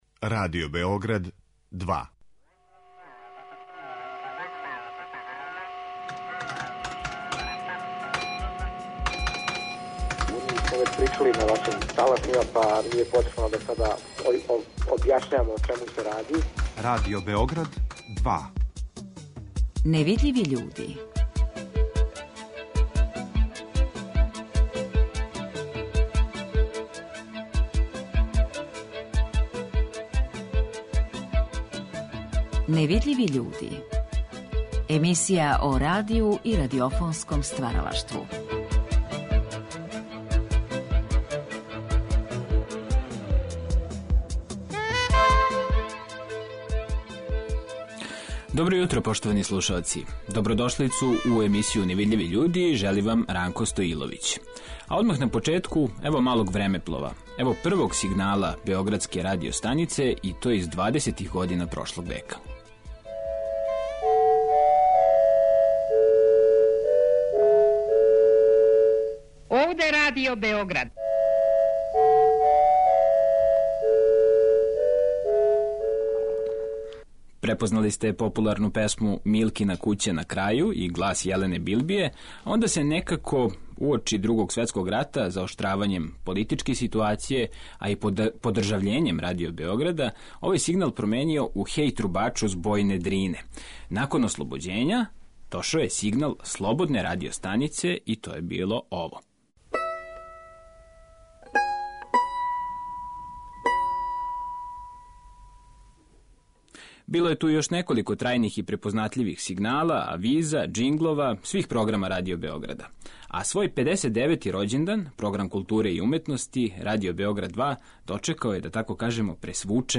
Биће речи о новом звучном идентитету, о његовој прилагођености природи и садржају програма, о тенденцијама у области звука код водећих светских радиофонских центара... Наравно, разговор ћемо проткати новим џингловима Радио Београда 2.